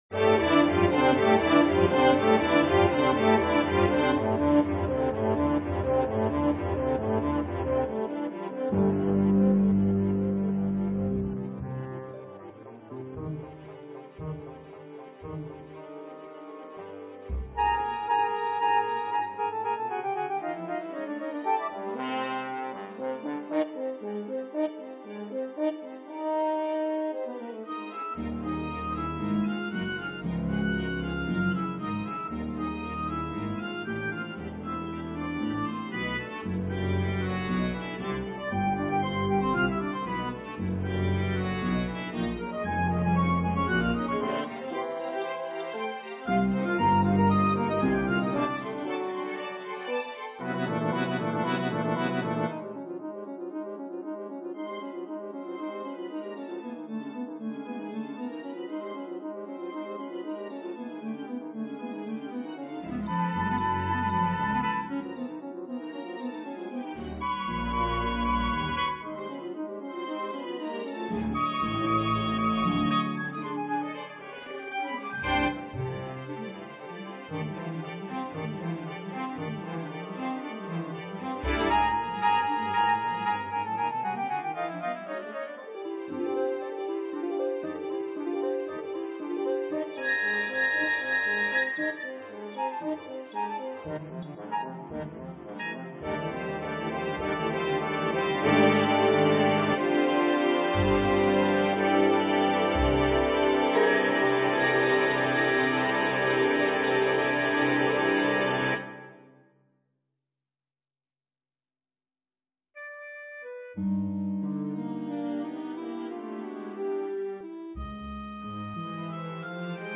Orchestration
Strings (Violin 1, Violin 2, Viola, Cello, Bass)